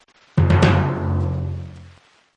Timpani sound
(This is a lofi preview version. The downloadable version will be in full quality)
JM_Tesla_Lock-Sound_Drums-Timpani-1_Watermark.mp3